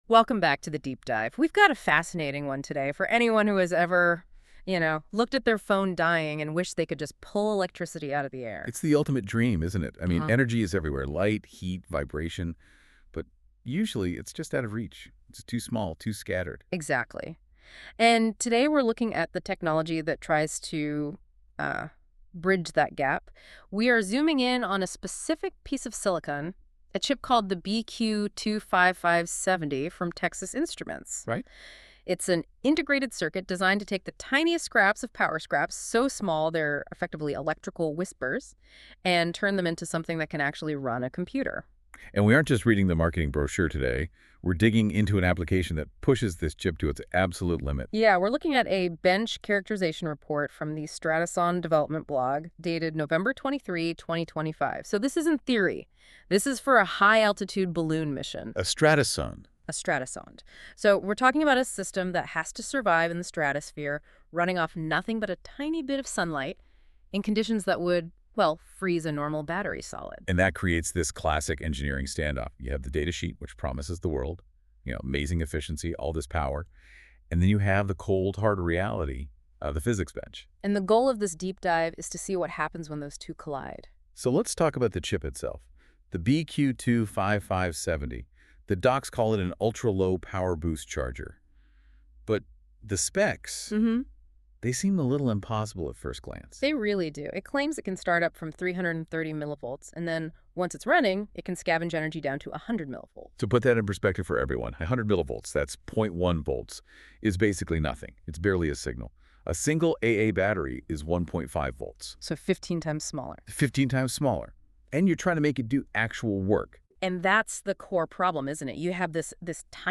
🎧 Listen to this article: NotebookLM Podcast An AI-generated audio discussion created by Google’s NotebookLM